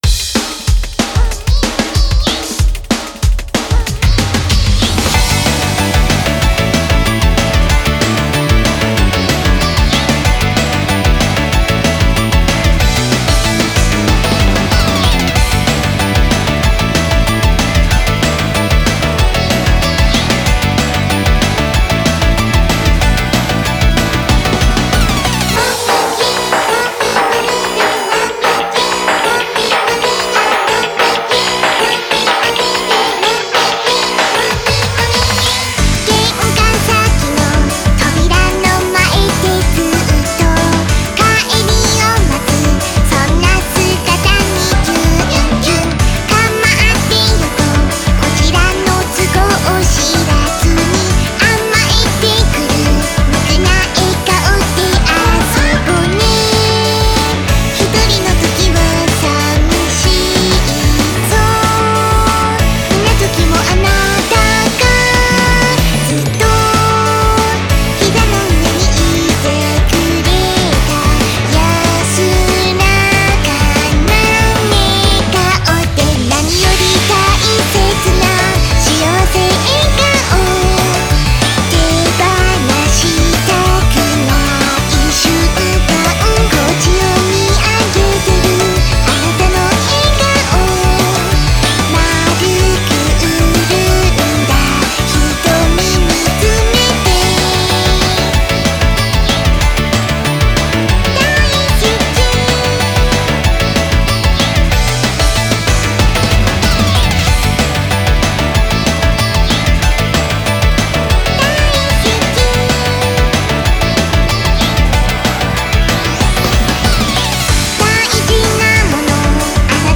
and the vocals are really cute to go with it.